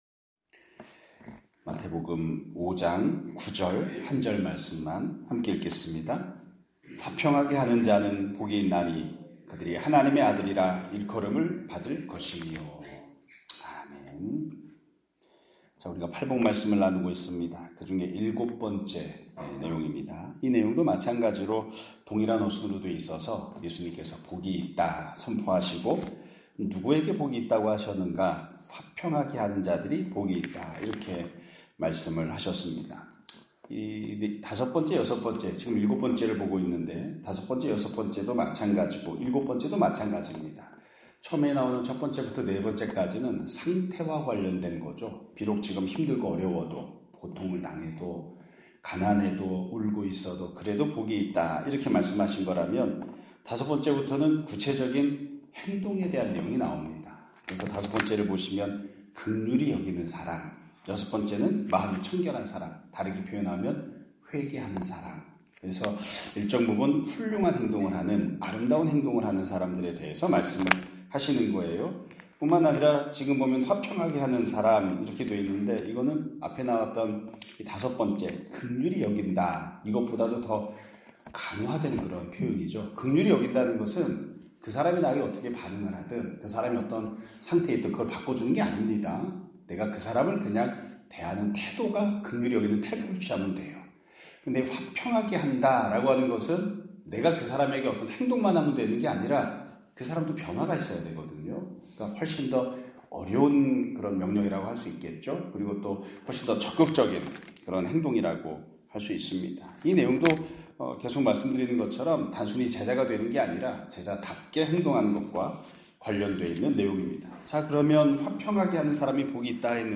2025년 5월 15일(목요일) <아침예배> 설교입니다.